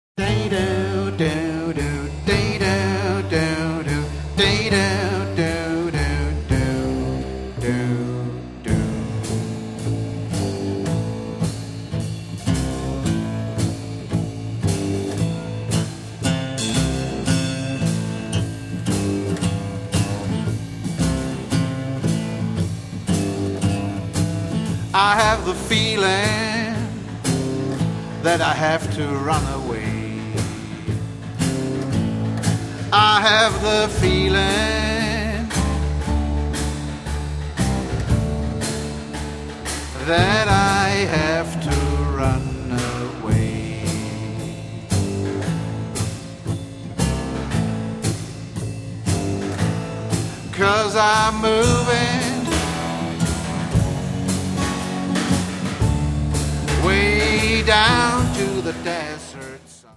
harmonica, washboard